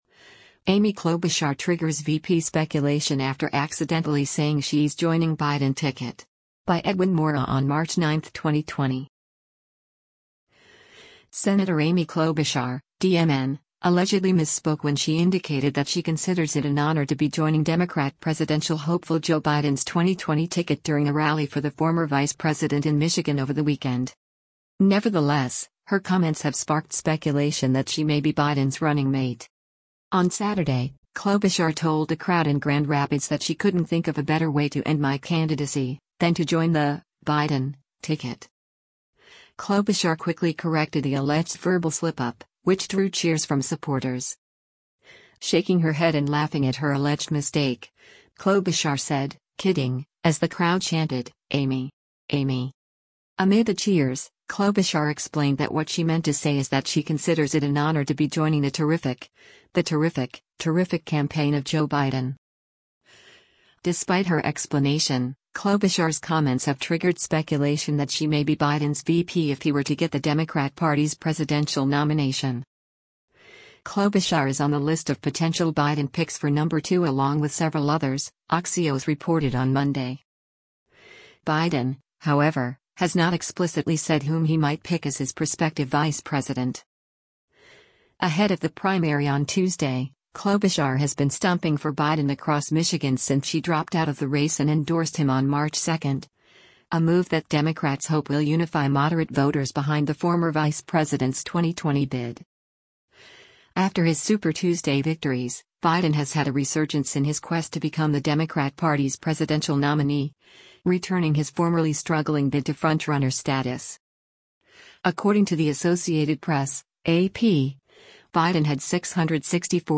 On Saturday, Klobuchar told a crowd in Grand Rapids that she “couldn’t think of a better way to end my candidacy … than to join the [Biden] ticket.”
Klobuchar quickly corrected the alleged verbal slip-up, which drew cheers from supporters.
Shaking her head and laughing at her alleged mistake, Klobuchar said, “Kidding,” as the crowd chanted, “Amy! Amy!”